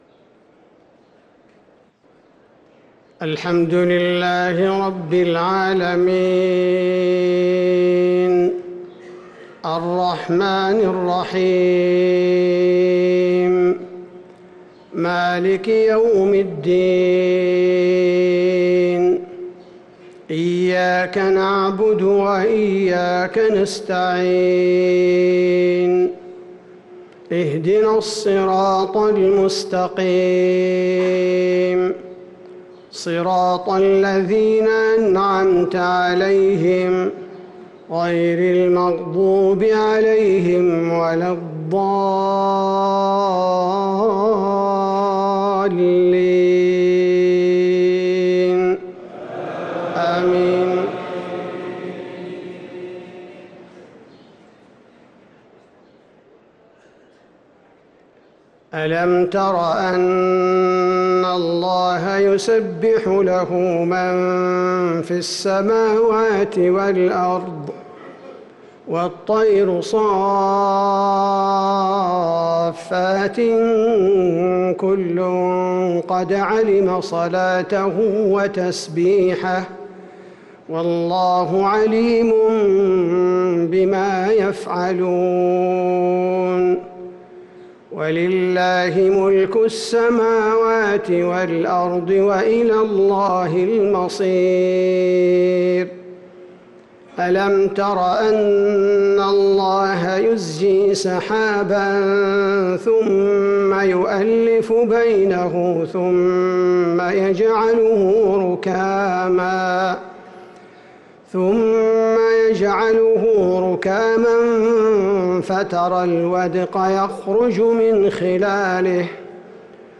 صلاة المغرب للقارئ عبدالباري الثبيتي 8 ربيع الأول 1445 هـ